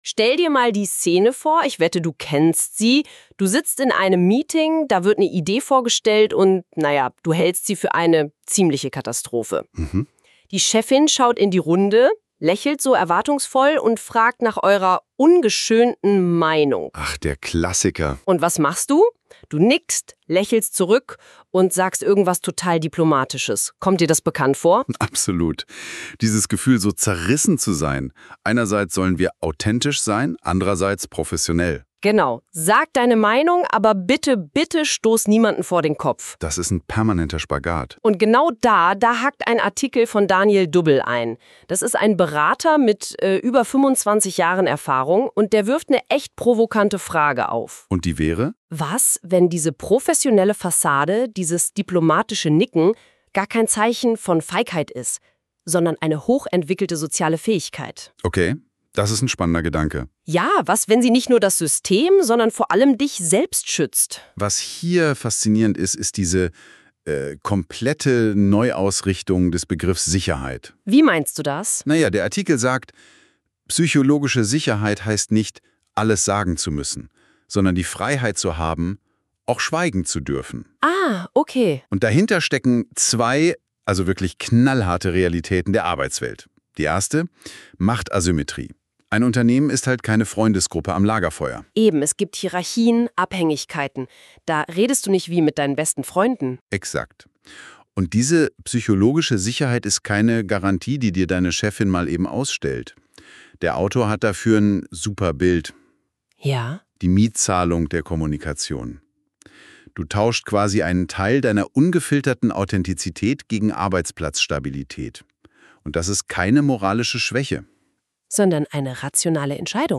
Hier bekommst du einen durch NotebookLM generierten KI-Podcast Dialog zu diesem Artikel.